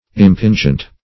Impingent \Im*pin"gent\